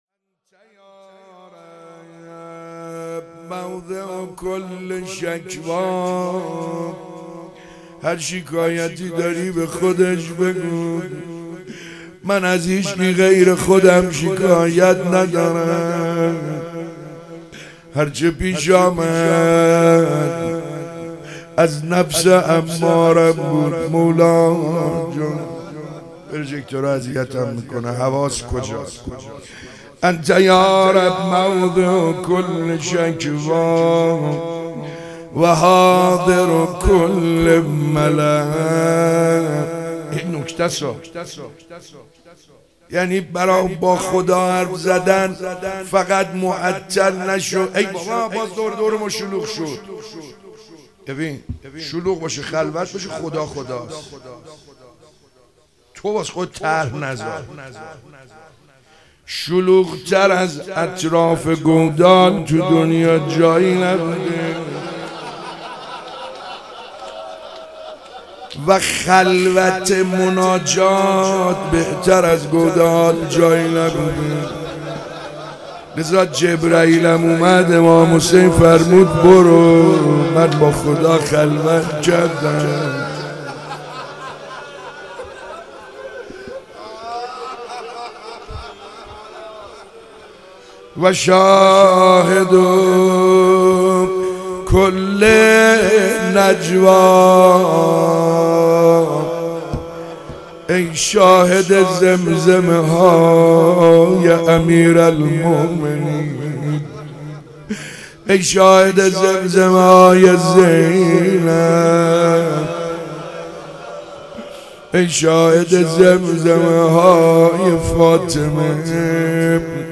فاطمیه 95_ روز دوم_مناجات